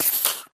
creeper4.ogg